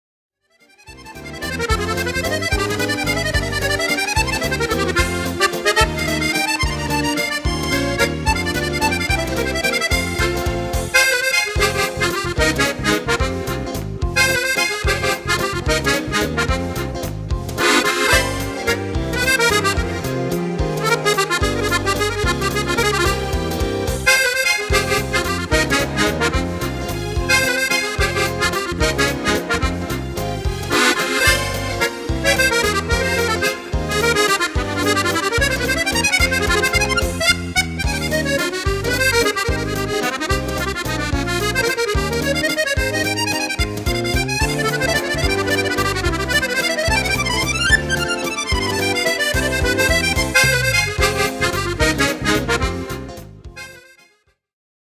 Valzer
Fisarmonica